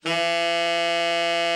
TENOR 10.wav